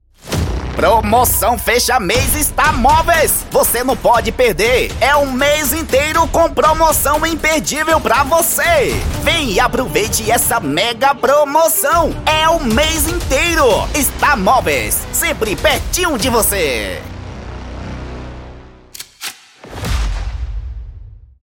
DEMONSTRATIVO IMPACTANTE: